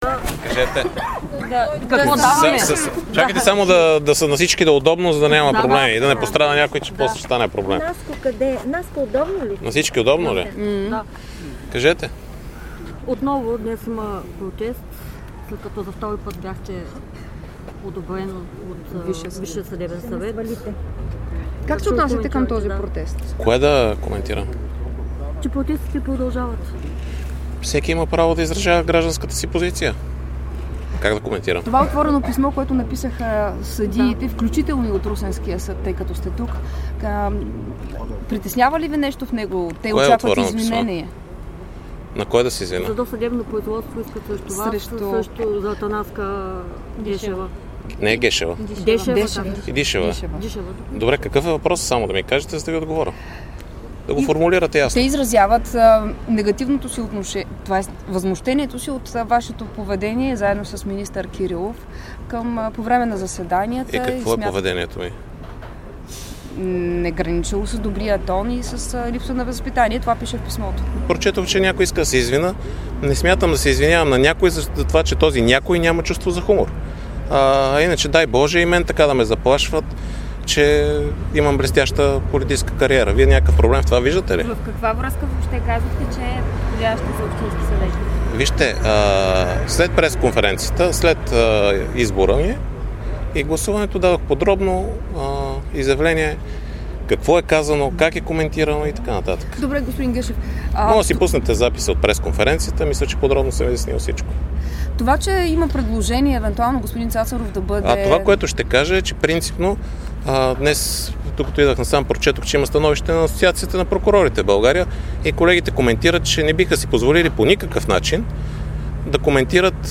Пълен запис на изказването на Иван Гешев в Русе